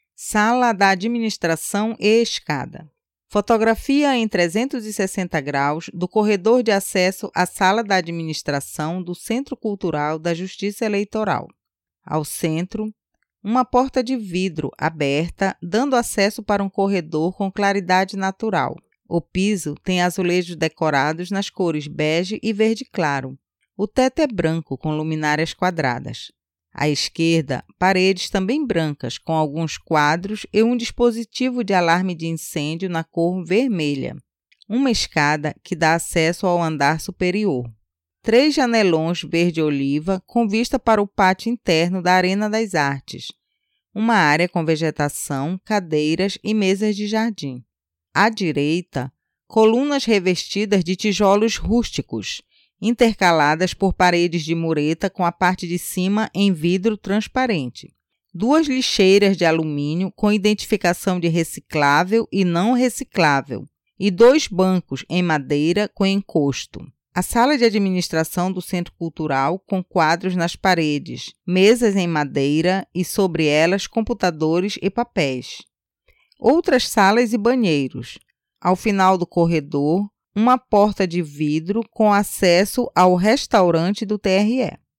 Sala de Administração e Escada audiodescrição